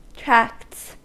Ääntäminen
Ääntäminen US Haettu sana löytyi näillä lähdekielillä: englanti Tracts on sanan tract monikko.